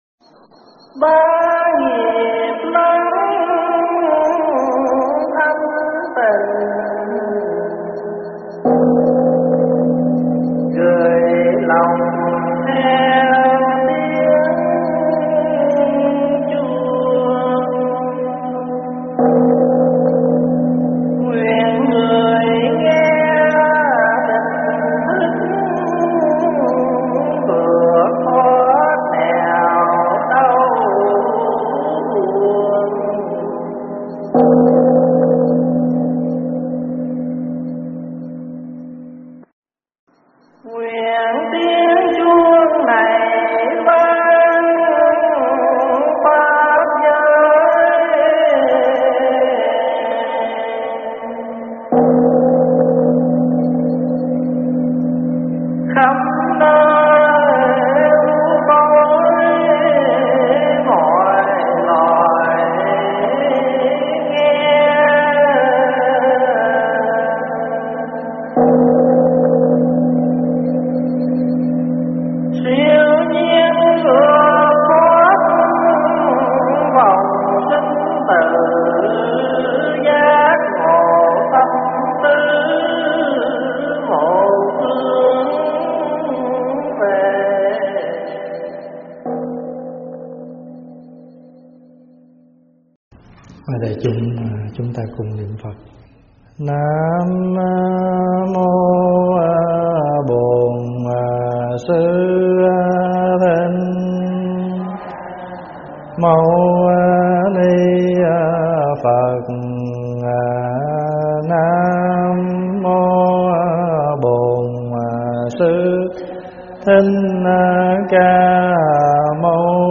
Mp3 thuyết pháp Bồ Tát Tại Gia 93